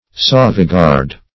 sauvegarde - definition of sauvegarde - synonyms, pronunciation, spelling from Free Dictionary Search Result for " sauvegarde" : The Collaborative International Dictionary of English v.0.48: Sauvegarde \Sau`ve*garde"\, n. [F.]